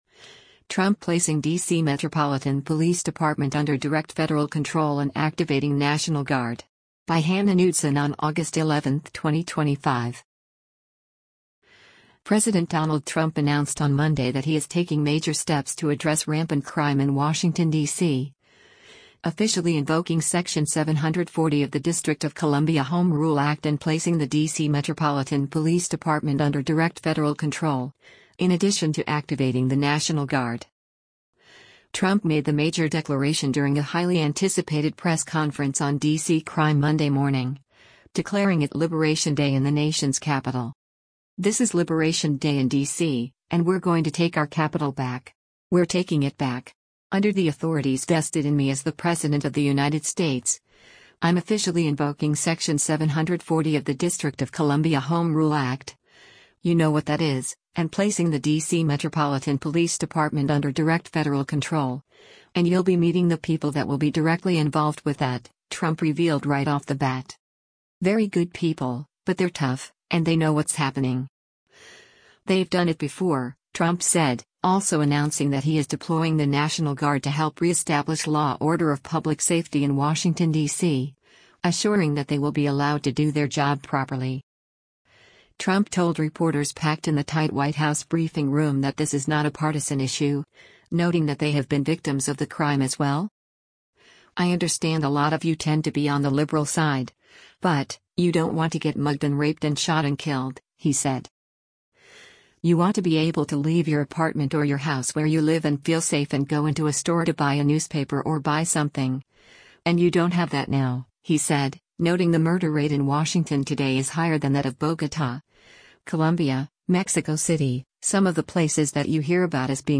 Trump made the major declaration during a highly-anticipated press conference on D.C. crime Monday morning, declaring it “Liberation Day” in the nation’s capital.
Trump told reporters packed in the tight White House briefing room that this is not a partisan issue, noting that they have been victims of the crime as well.